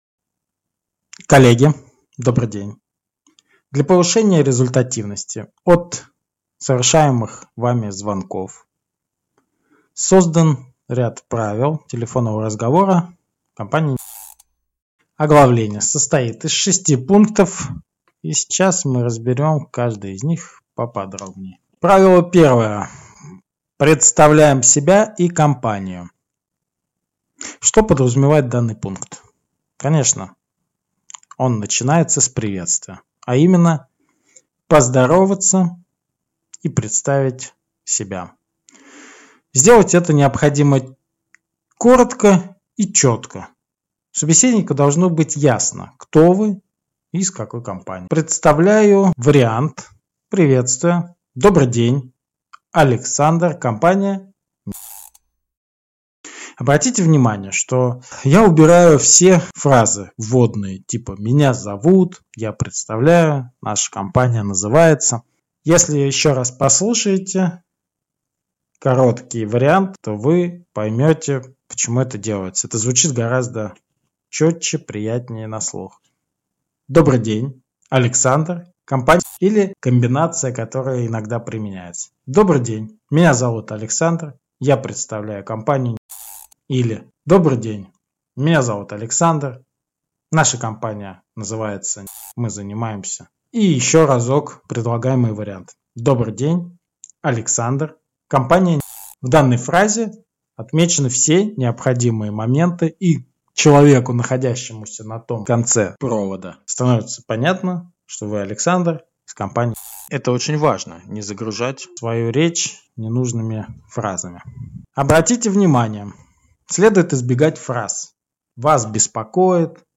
Аудиокнига Правила телефонных переговоров. От телёнка до золотого тельца…